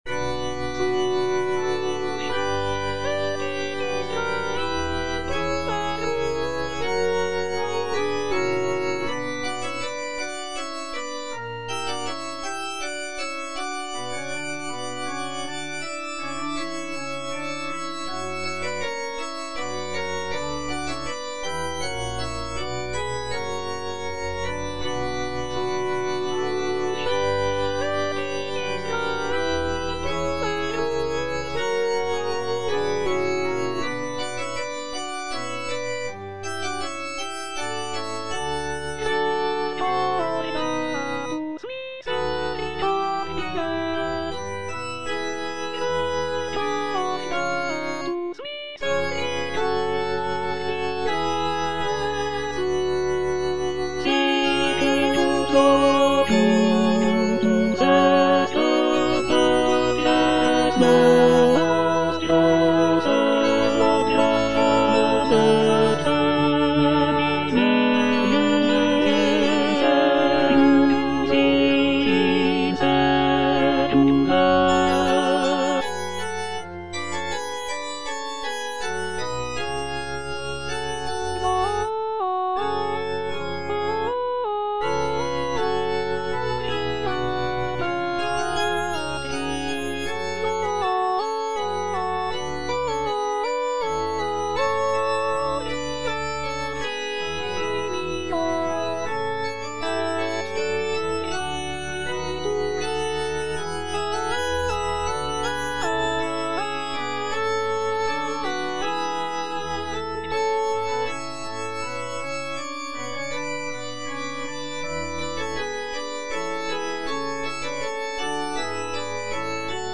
J.D. ZELENKA - MAGNIFICAT IN D MAJOR ZWV108 Suscepit - Tenor (Emphasised voice and other voices) Ads stop: auto-stop
The "Magnificat ZWV108" is a choral work composed by Jan Dismas Zelenka, a Czech Baroque composer.
The composition showcases Zelenka's remarkable contrapuntal skills, with intricate vocal lines and rich harmonies.